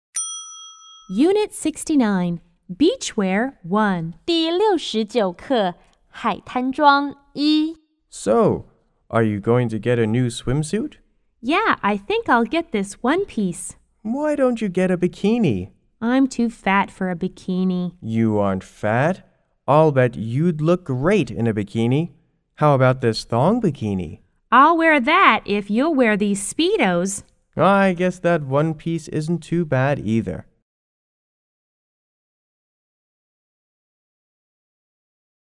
B= Boy G=Girl